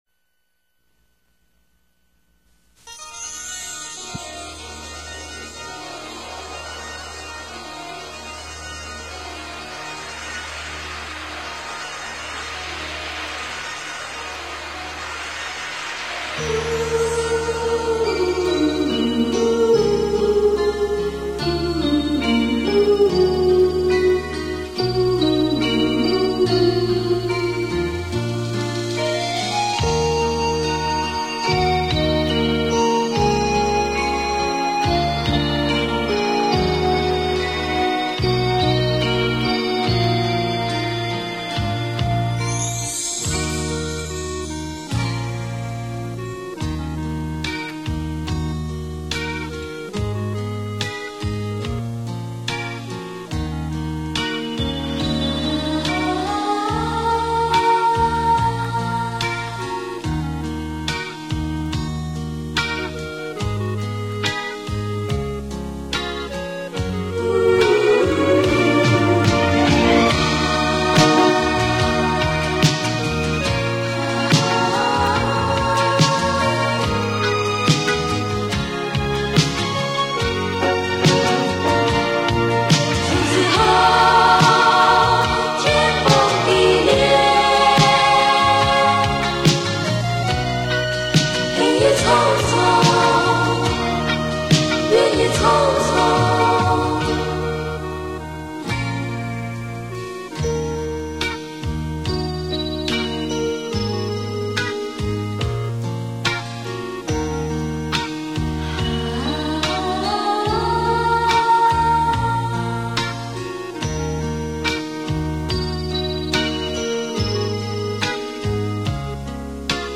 KTV版伴奏